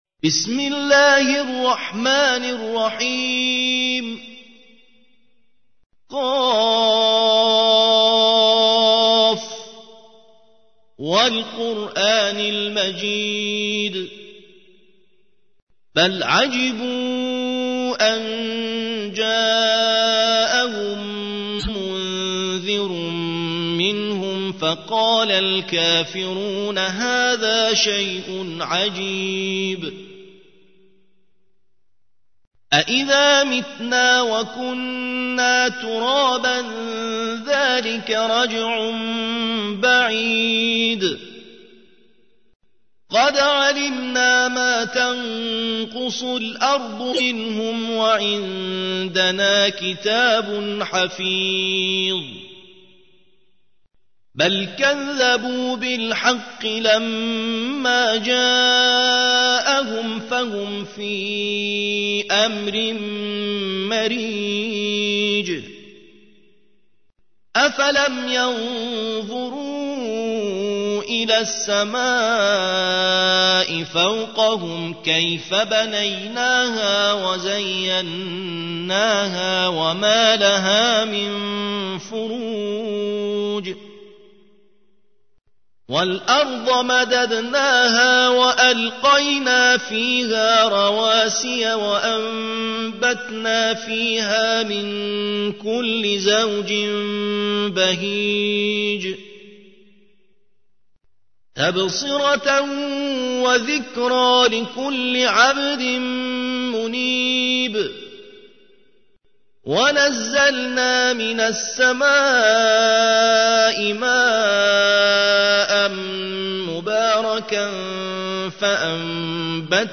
50. سورة ق / القارئ